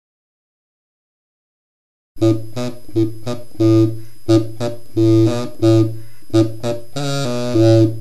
URM Sonos de Sardigna :Rielaborazioni - Crocorigofono
CrocorigofonoGrande.mp3